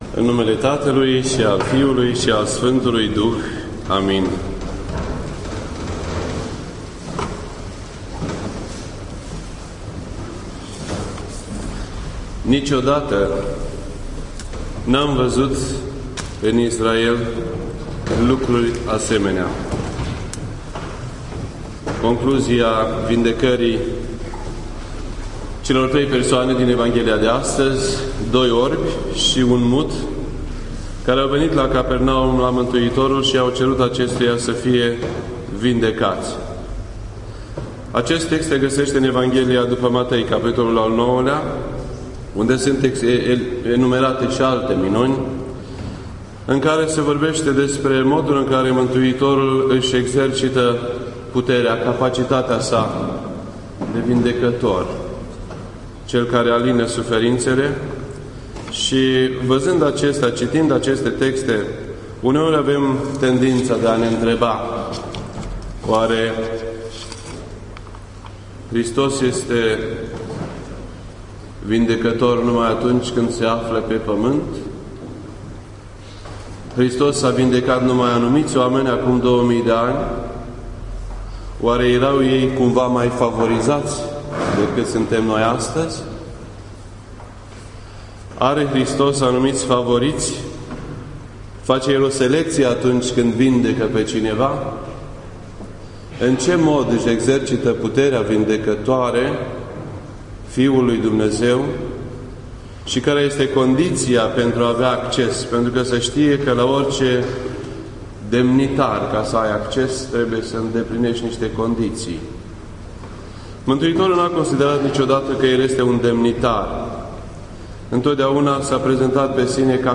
This entry was posted on Sunday, July 22nd, 2012 at 8:03 PM and is filed under Predici ortodoxe in format audio.